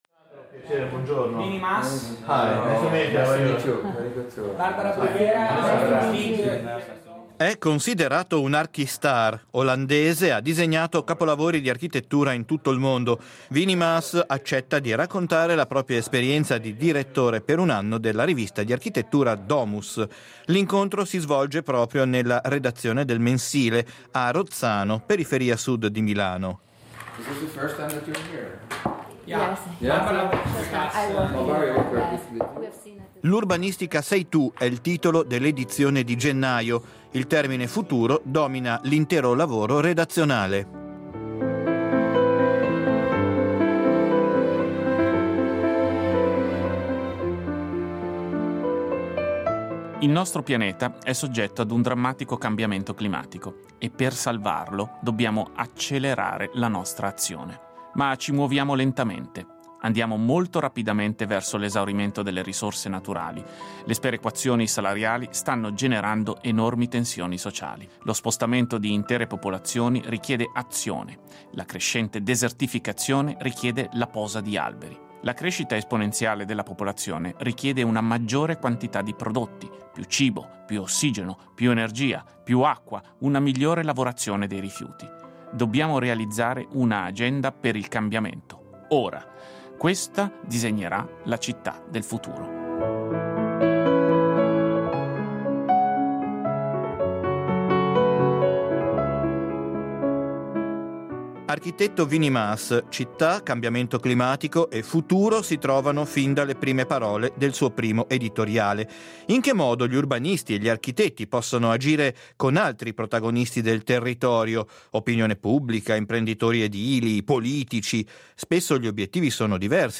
Incontro con l’architetto olandese Winy Maas